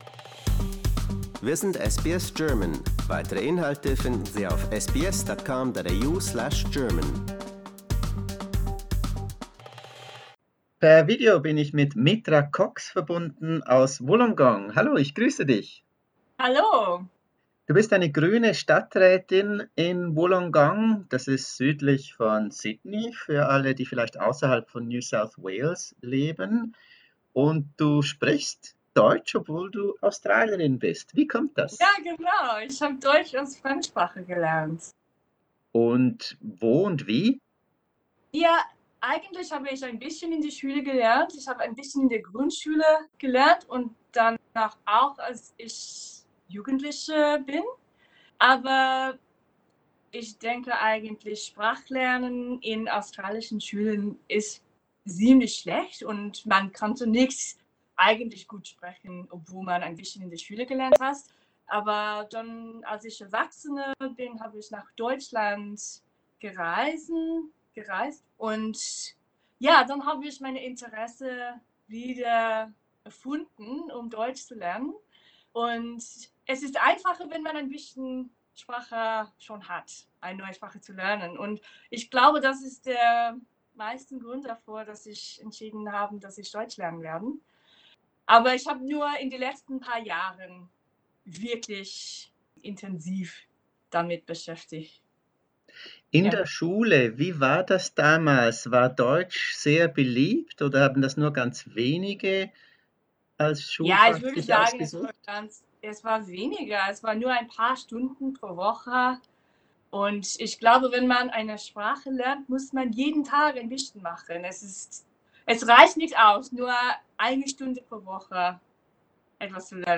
Wie kommt das und was sagen ihre Freunde? Ein Interview mit der lebenslustigen Australierin.